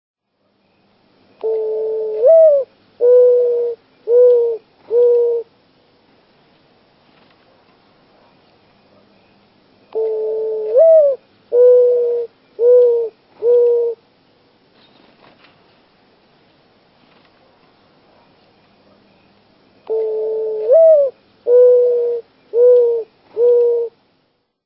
Mourning Dove
Its mournful call is heard from deserts to forest edges, from farmlands to inner cities.
Bird Sound
Song a plaintive Òcoo-OOH, Ooo-Ooo-OooÓ. Wings whistle in flight.
MourningDove.mp3